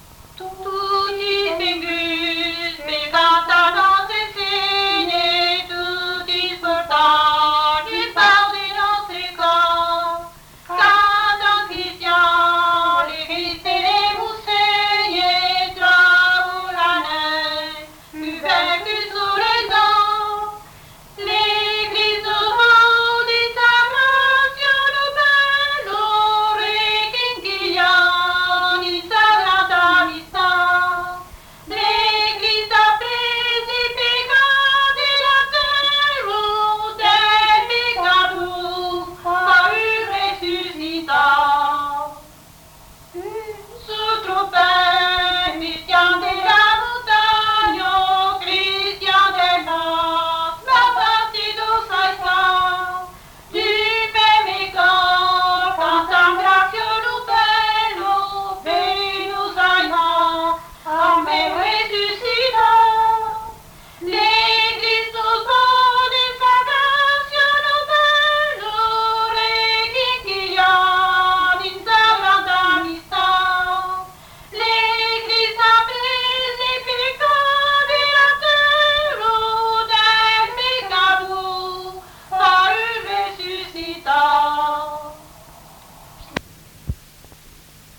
Lieu : Mas-Cabardès
Genre : chant
Effectif : 2
Type de voix : voix de femme
Production du son : chanté
Description de l'item : fragment ; 2 c. ; refr.
Classification : noël